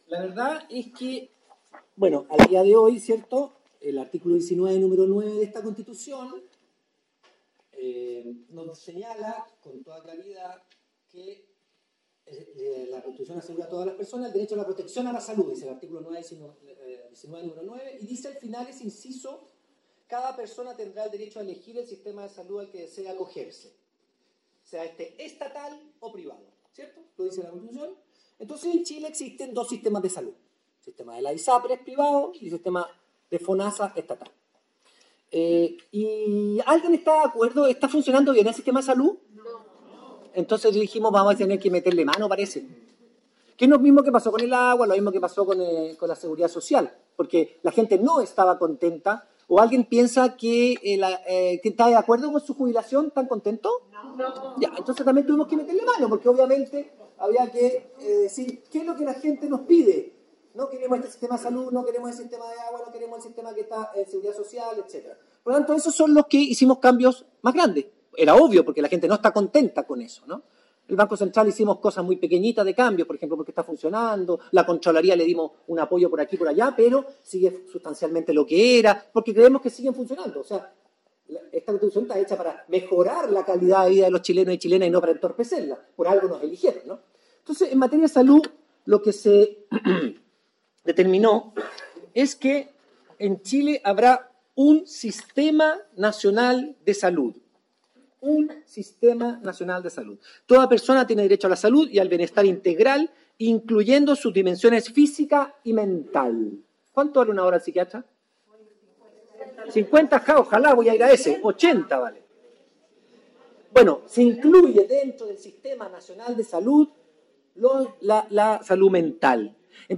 En un conversatorio abierto sobre la Nueva Constitución, el abogado y ex convencional constituyente, Daniel Stingo, esclareció, con estilo ameno y coloquial, las dudas y consultas de los vecinos, que aparte de interés, mostraron notable conocimiento de los temas.
La actividad, organizada por el Comando Amplio Ancud Aprueba de Salida, se realizó en la sede social del sector Alto de Caracoles, el pasado sábado 23.